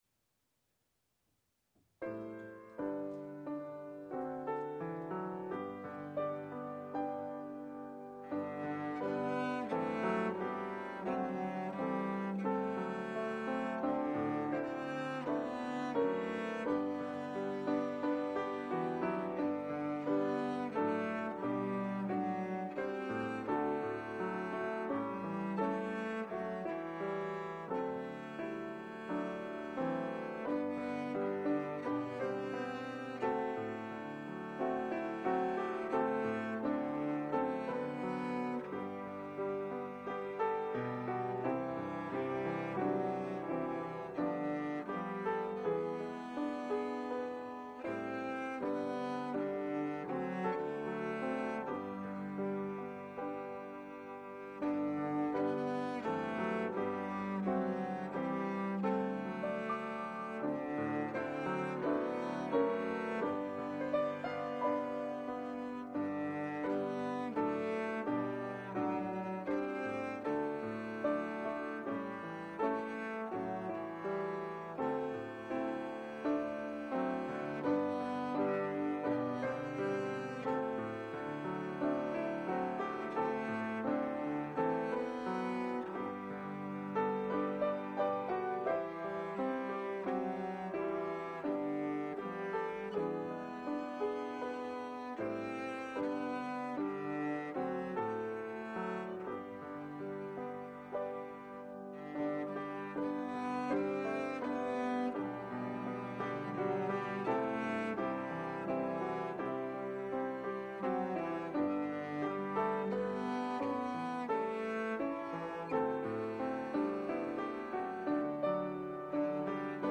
hymns on cello and piano